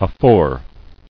[a·fore]